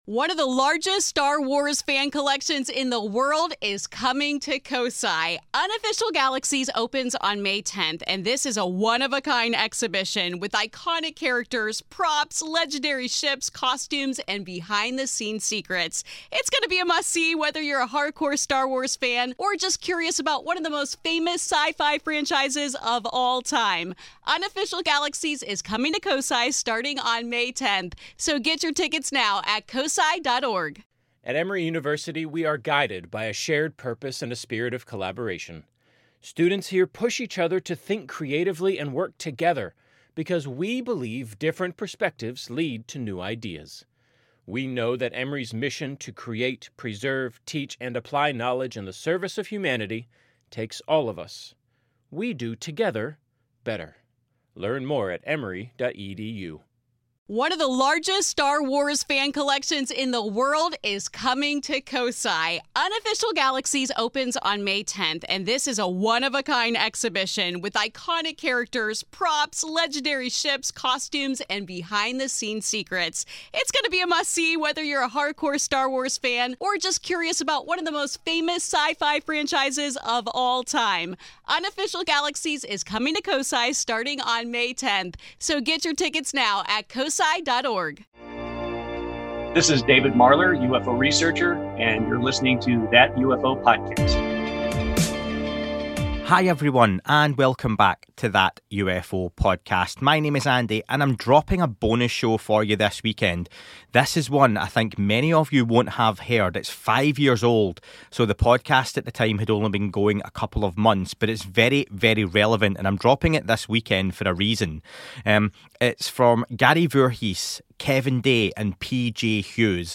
These are three witnesses to the Tic Tac event of November 2004, all from differing expertise and vantage points.